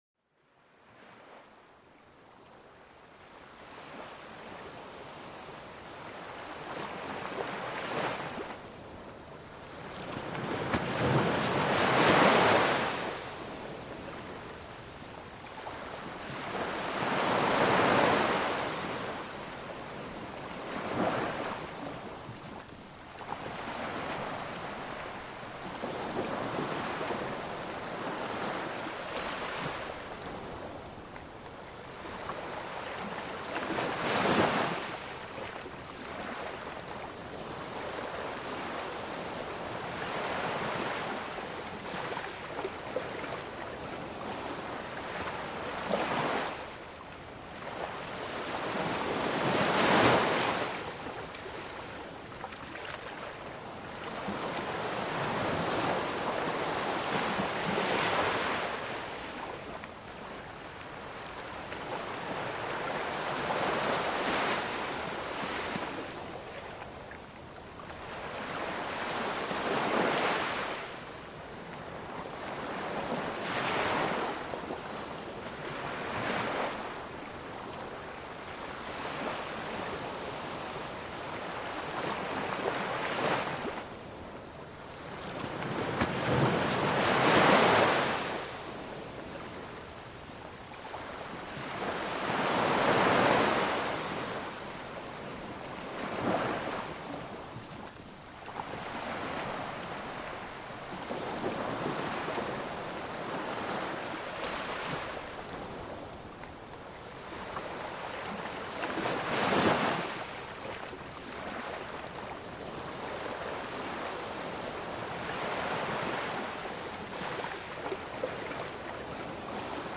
Plongez dans l'immensité des océans à travers leurs sons captivants et apaisants.Cet épisode vous invite à un voyage où le bruissement des vagues devient une symphonie naturelle, berçant votre esprit.Découvrez comment le son des vagues peut réduire le stress et favoriser une profonde relaxation.Ce podcast est une expérience audio immersive qui plonge les auditeurs dans les merveilleux sons de la nature.